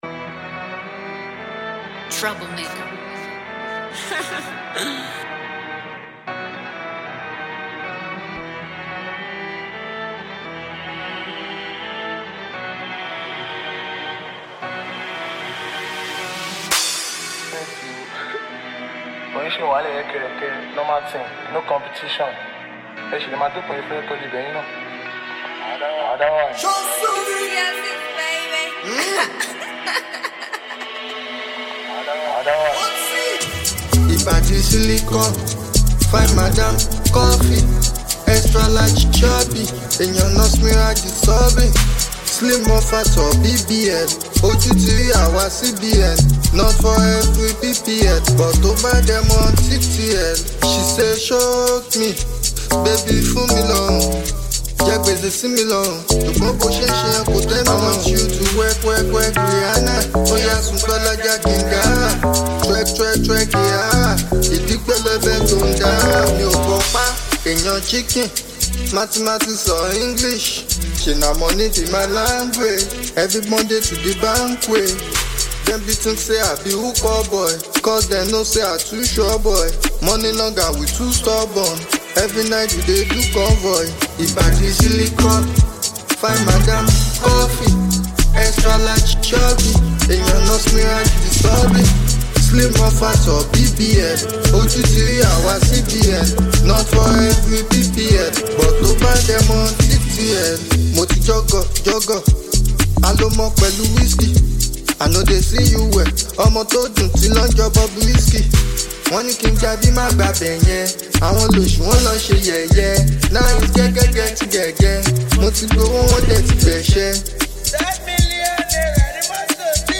an energetic party anthem